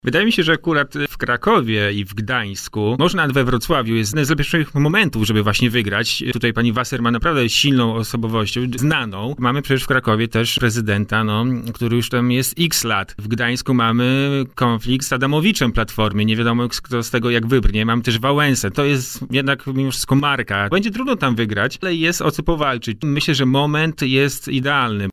Wiadomości Z Warszawy i regionu